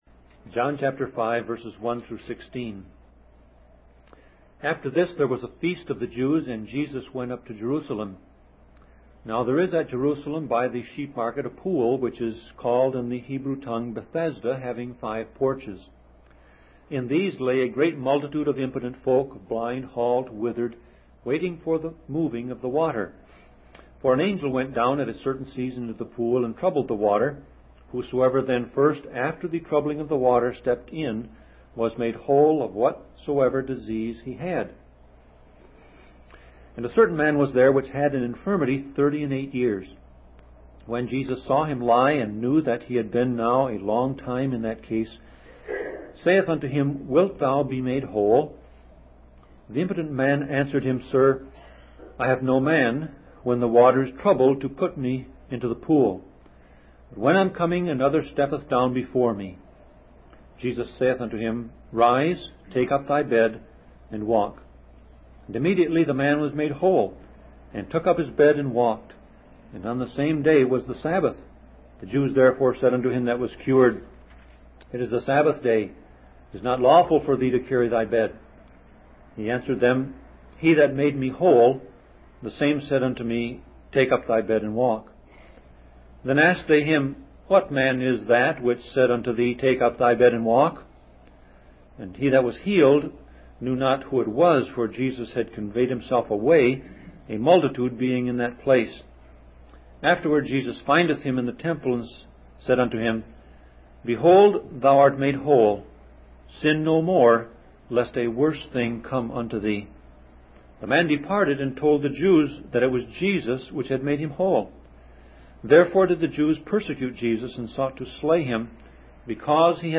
Sermon Audio Passage: John 5:1-16 Service Type